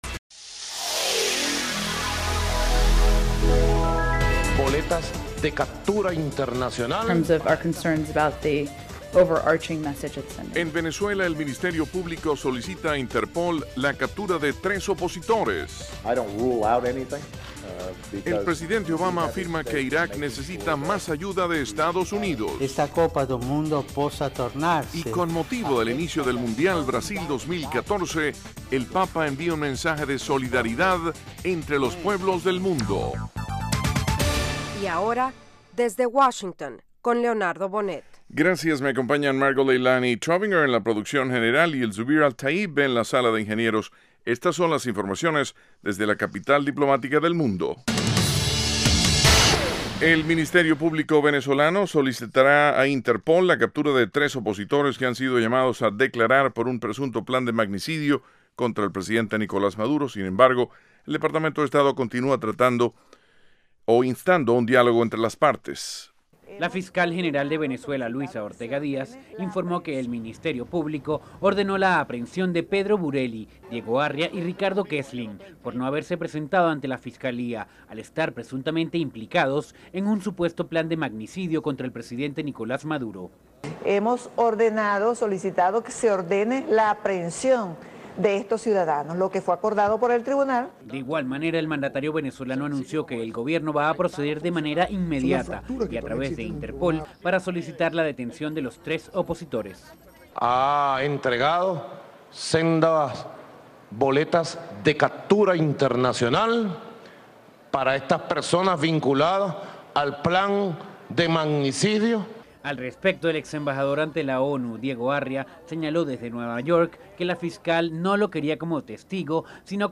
Con entrevistas a líderes políticos, nacionales y extranjeros, Desde Washington ofrece las últimas noticias sobre los acontecimientos que interesan a nuestra audiencia. El programa se transmite de lunes a viernes de 8:00 p.m. a 8:30 p.m. (hora de Washington).